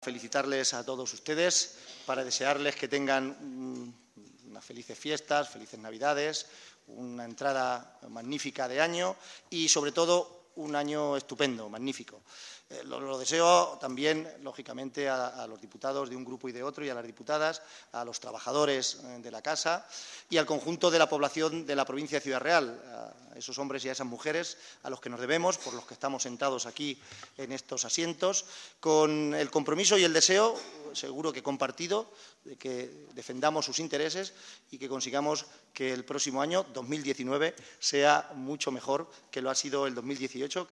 Buenos deseos y compromiso de trabajo por la provincia y por el bienestar de sus gentes en el último Pleno del año
Mensaje de Navidad Presidente Diputación de Ciudad Real